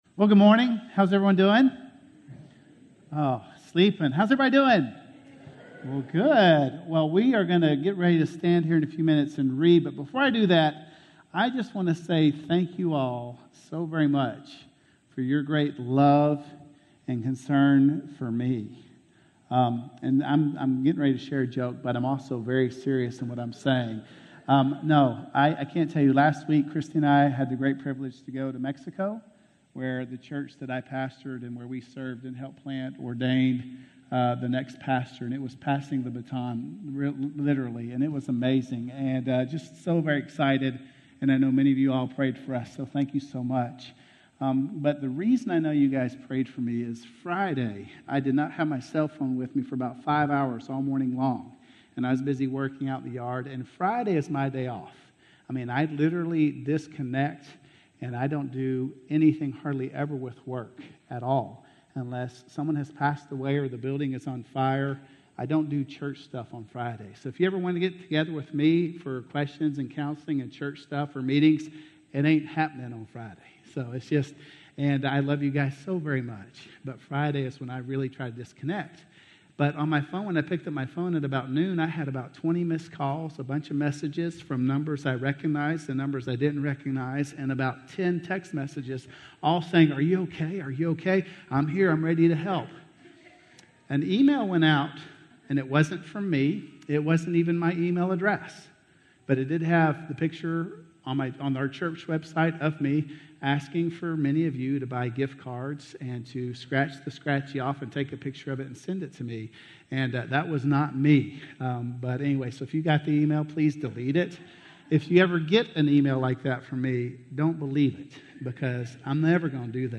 Letting Go of Grief - Sermon - Woodbine